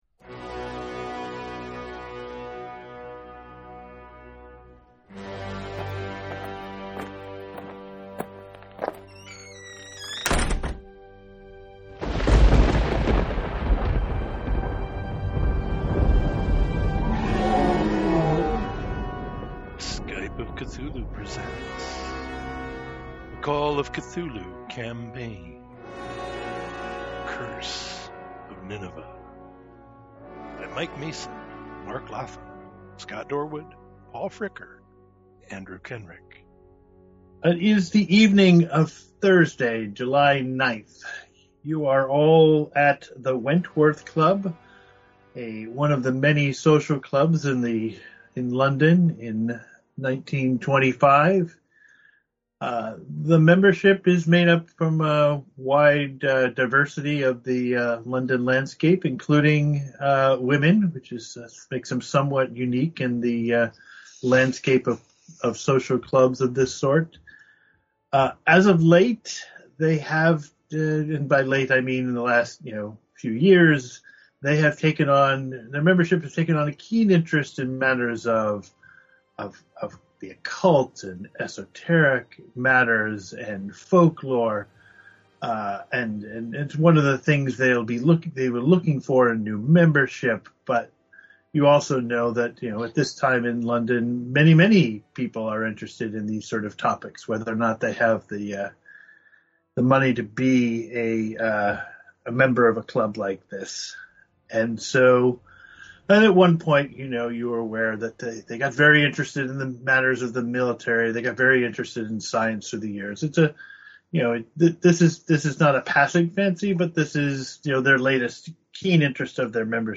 Call of Cthulhu, and perhaps other systems on occasion, played via Skype.